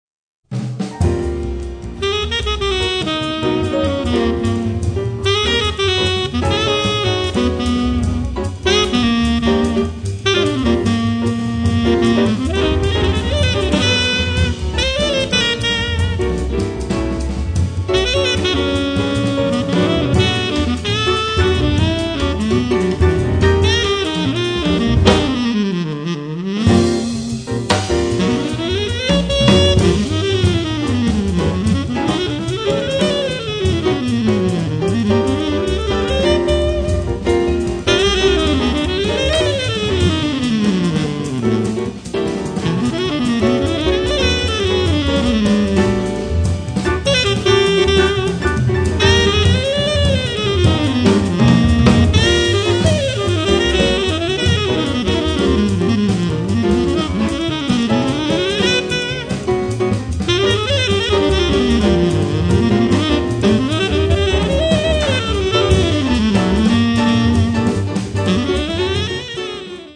tenor sax
piano
Bass
Drums